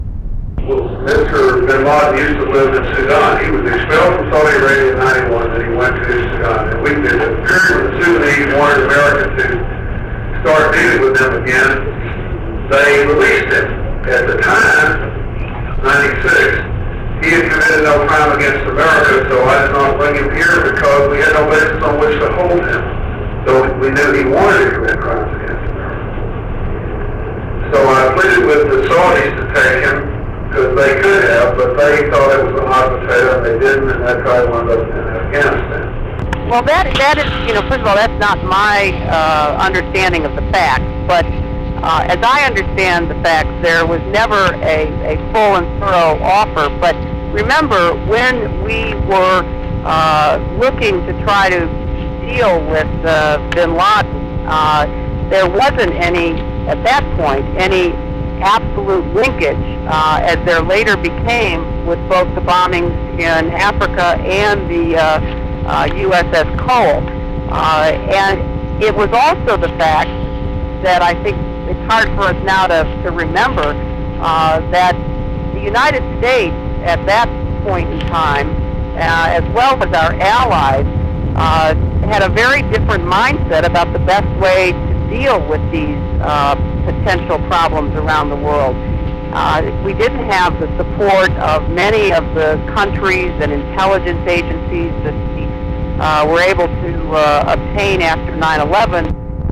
Hear Clinton describe